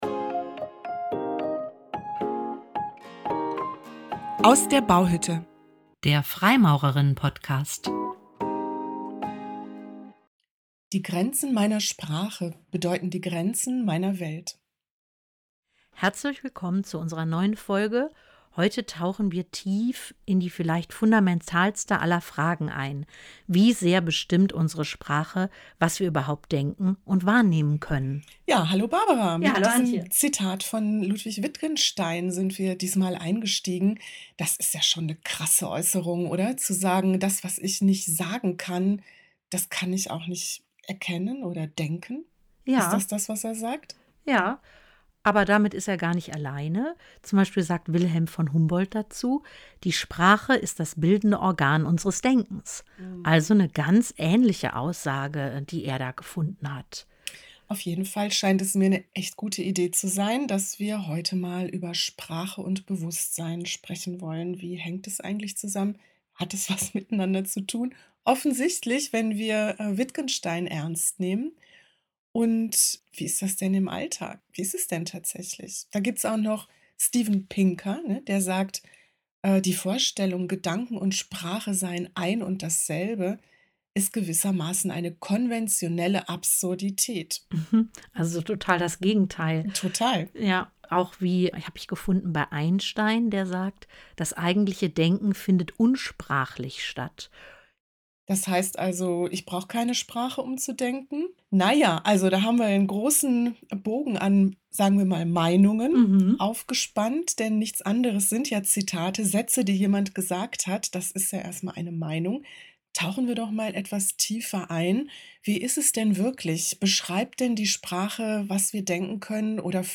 Ausgehend von verschiedenen philosophischen Theorien untersuchen sie im Dialog, in wieweit Sprache die ordnende Macht der Gedanken sein kann, in wieweit sie ein Instrument der Gedankenformung ist und welchen Einfluss Framing und Priming bereits auf die Wahrnehmung von Wirklichkeit hat.